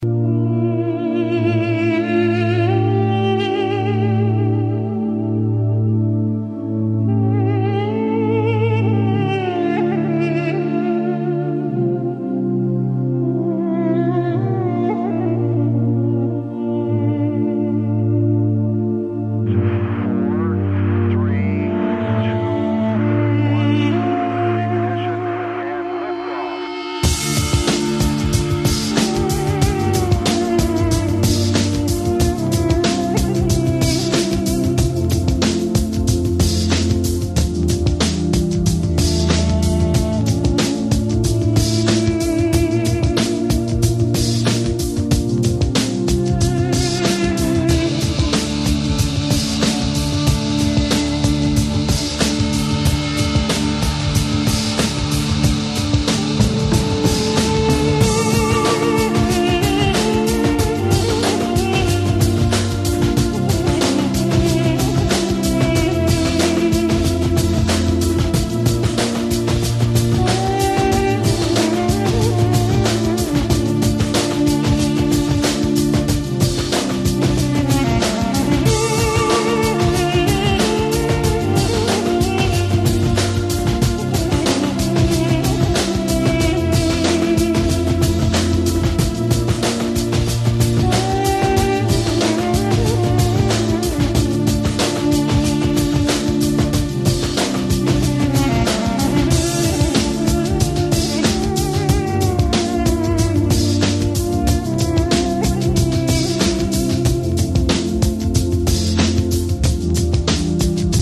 • Категория: Дудук
• Качество: Высокое